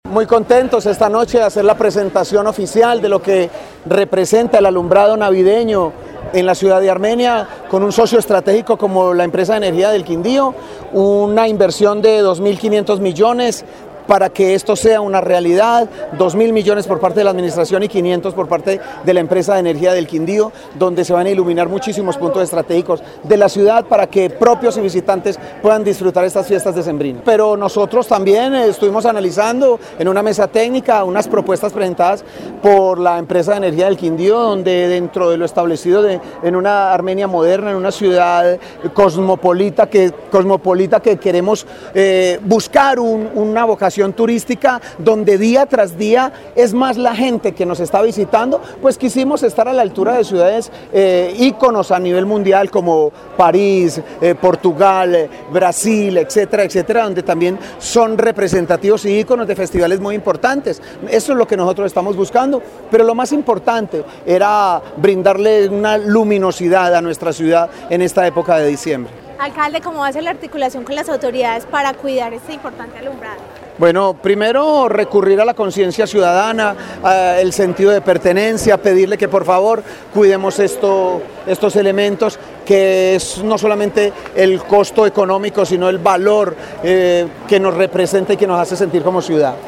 Alcalde de Armenia